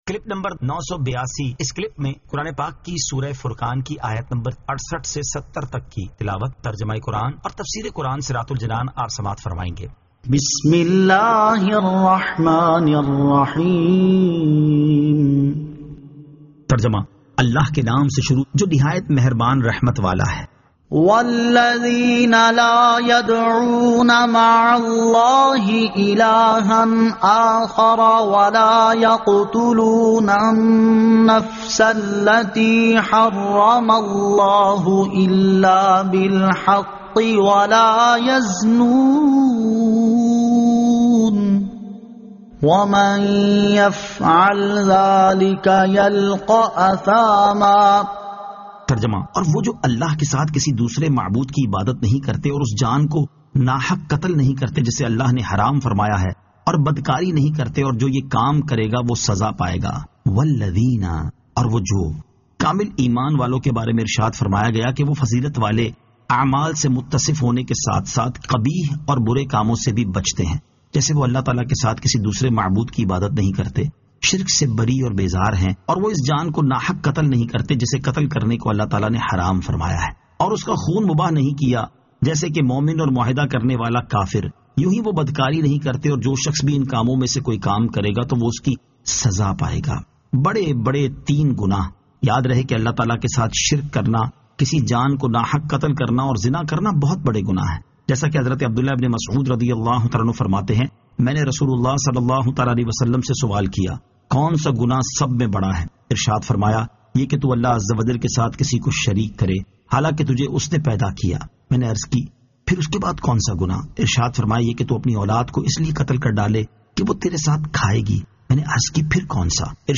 Surah Al-Furqan 68 To 70 Tilawat , Tarjama , Tafseer